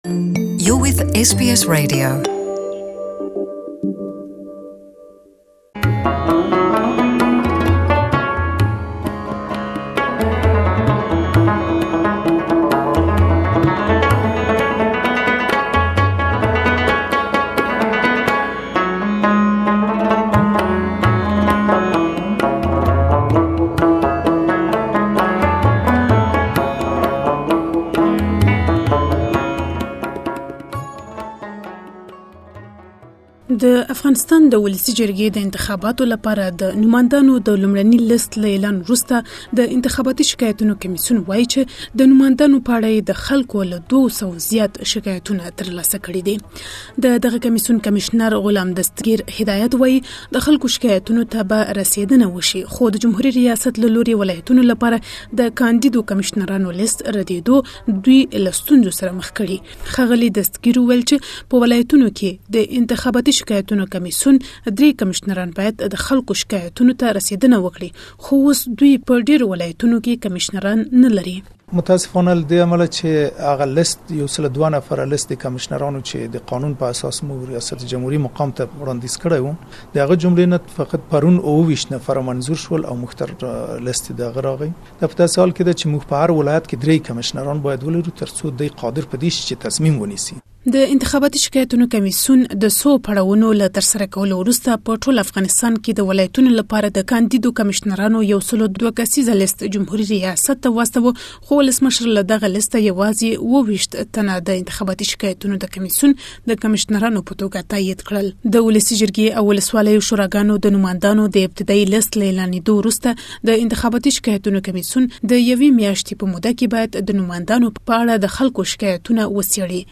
Afghanistan's Electoral Complaints Commission has received more that 200 complaints after the release of primary candidate list. For more details please listen to the full report in Pashto.